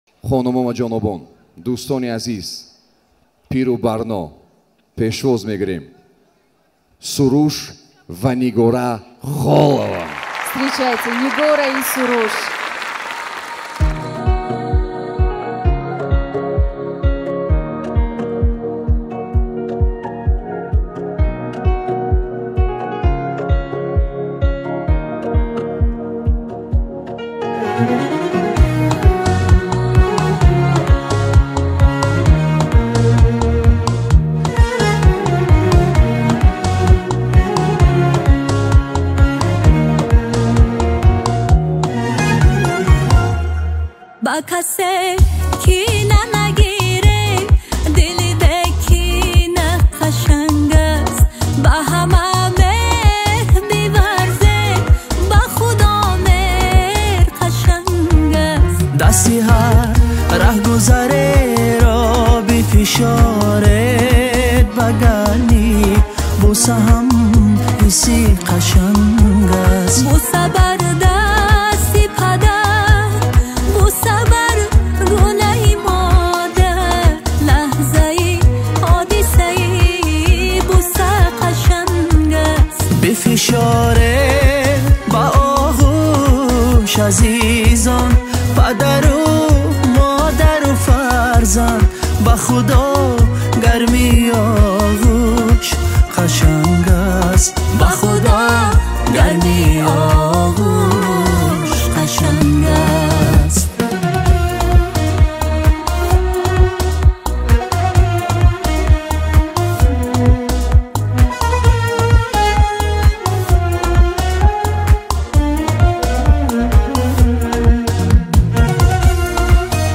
Категория: Таджикские